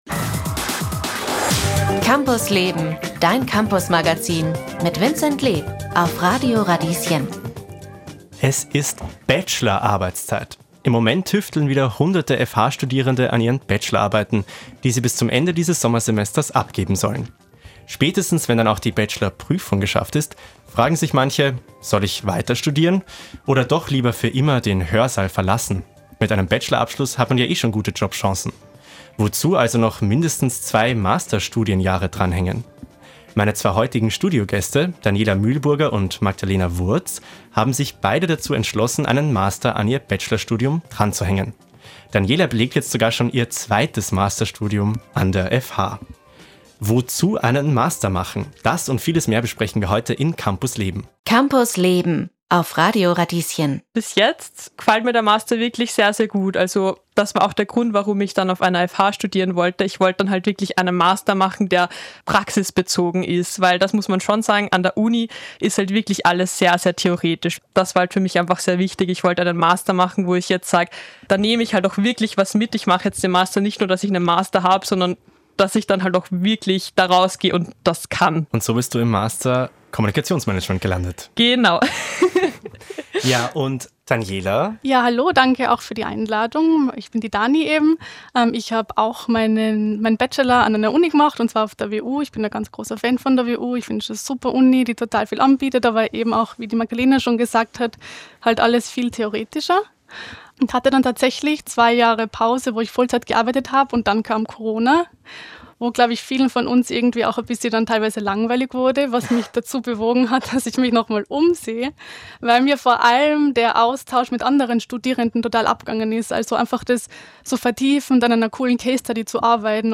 Dieser Podcast ist ein Ausschnitt aus der Campus Leben-Radiosendung vom 5. März 2025.